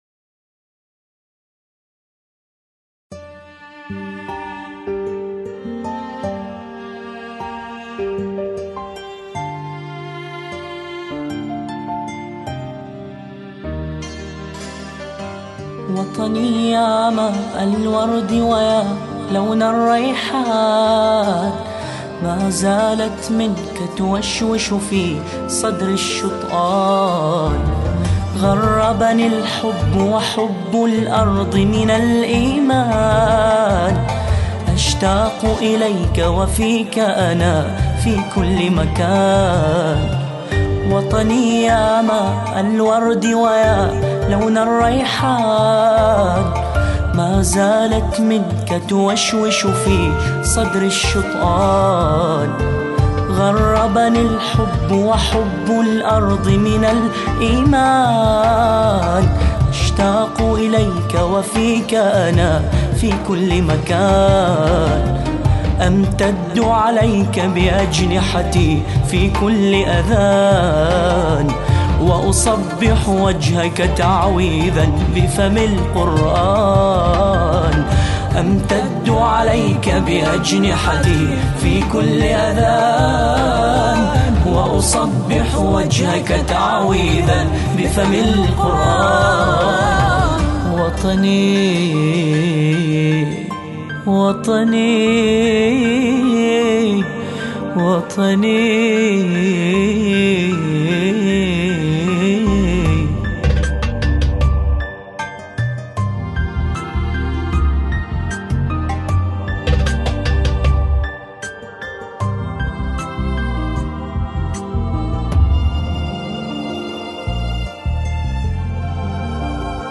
انشودة وطنية أناشيد بحرينية